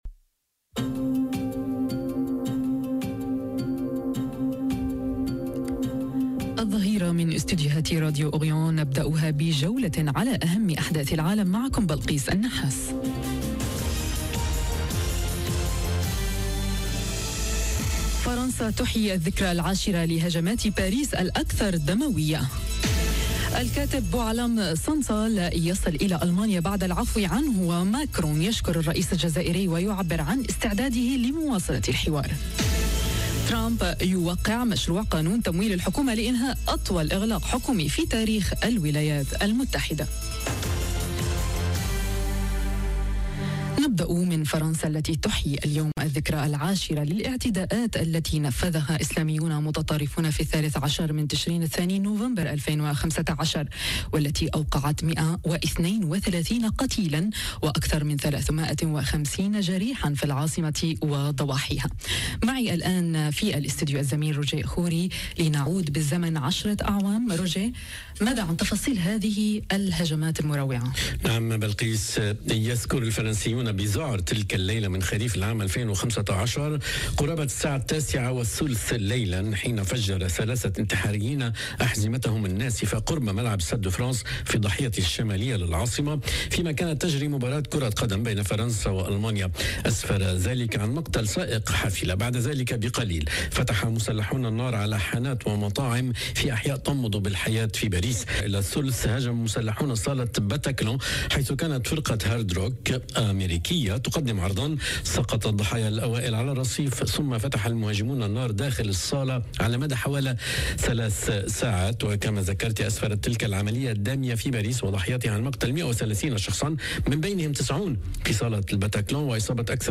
نشرة أخبار الظهيرة: فرنسا تحيي الذكرى العاشرة لهجمات باريس الأكثر دموية، والكاتب بوعلام صنصال يصل إلى ألمانيا بعد العفو عنه